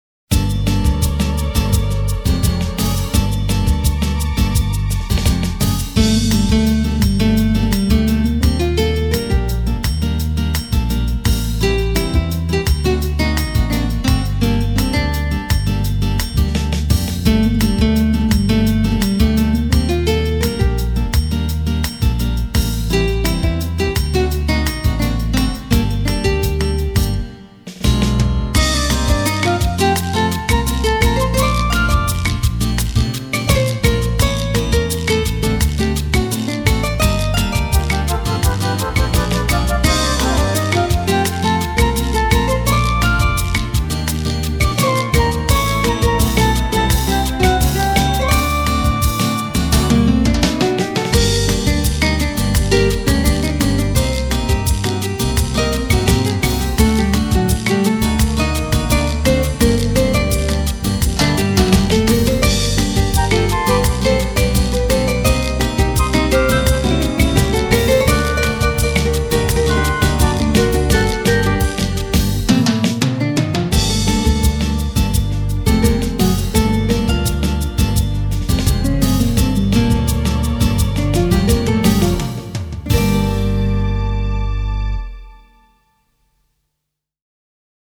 요즘과같이 선선하고 맑은 초여름저녁에 근처 공원이나 산책로 주변 공원 터에서 들으면 좋은 곡입니다.
그런데 이곡 기타가 어쿠스틱 + 미디기타 인가요?